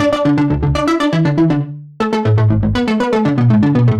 Index of /musicradar/french-house-chillout-samples/120bpm/Instruments
FHC_Arp A_120-E.wav